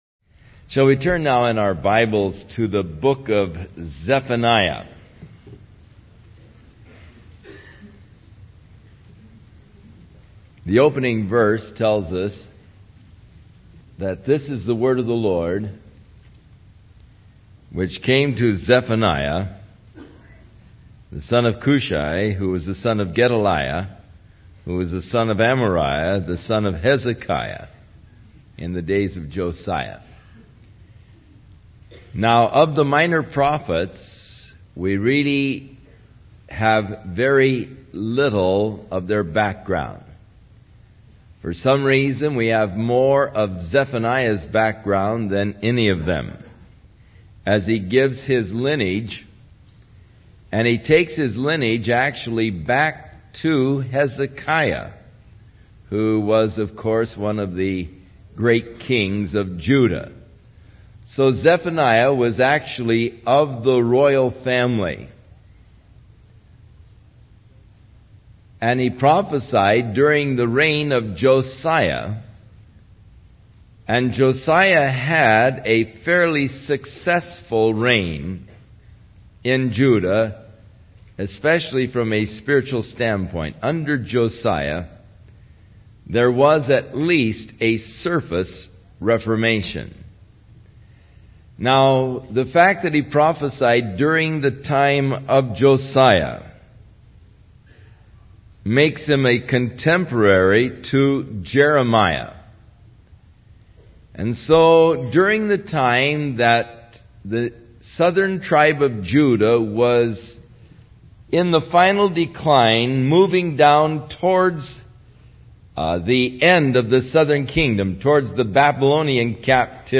01 Verse by Verse Teaching